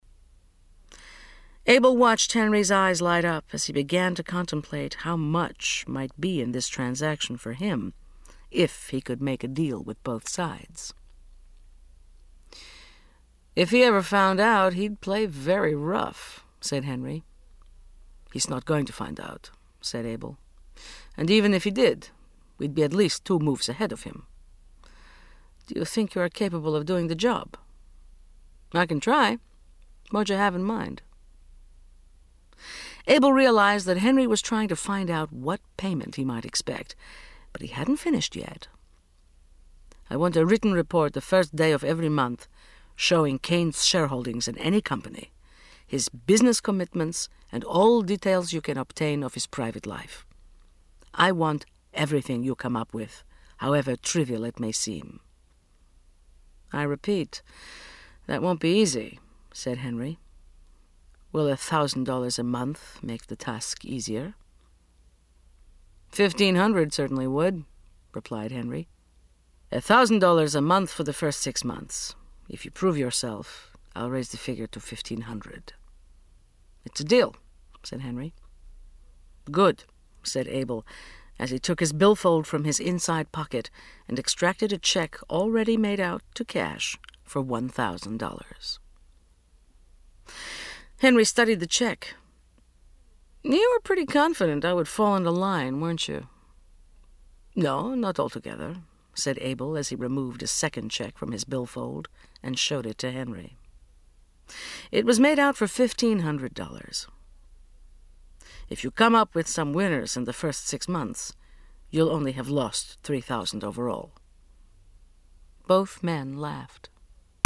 37serv Audiobooks/Jeffrey Archer - Prodigal Daughter (1982) (96)